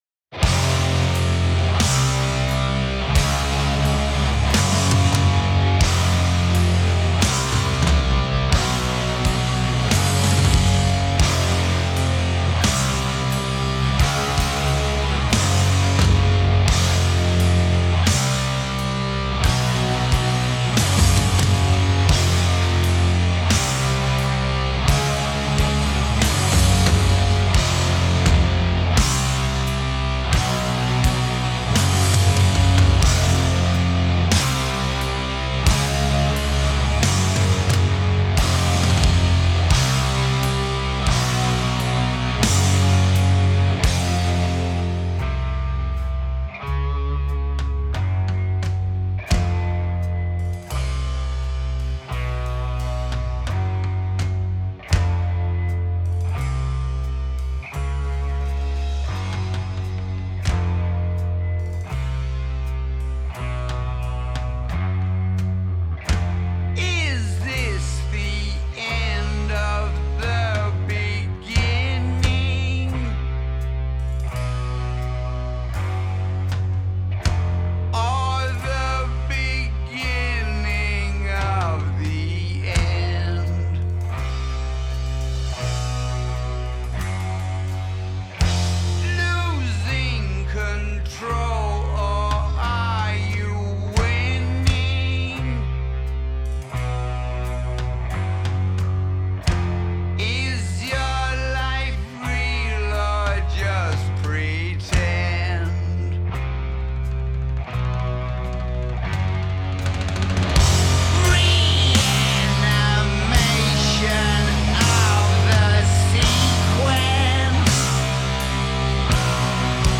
Heavy Metal, Doom Metal, Blues Metal